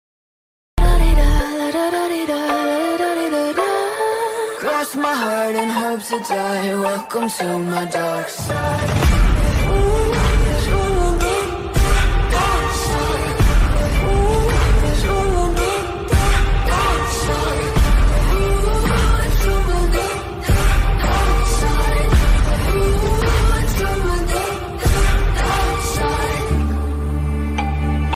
Alternative Ringtones